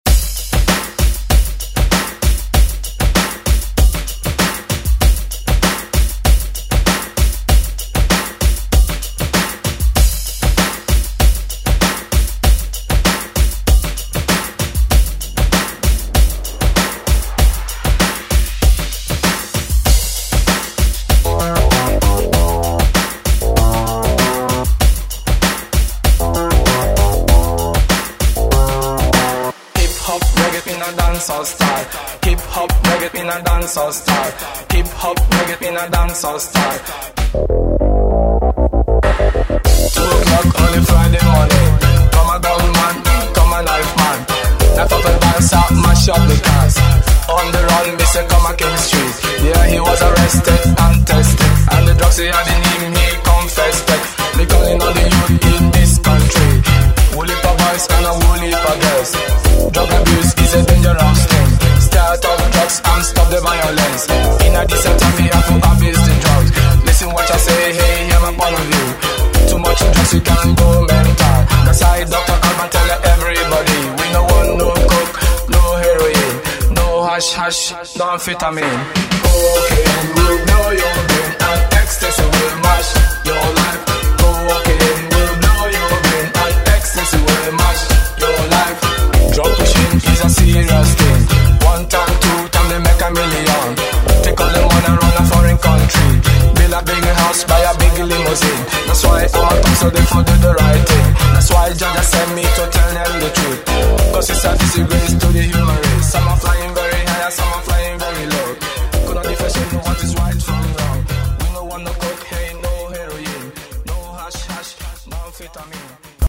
Genre: 80's Version: Clean BPM: 95 Time